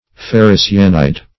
Ferricyanide \Fer`ri*cy"a*nide\ (?; 104), n. [Ferri- + cyanide.]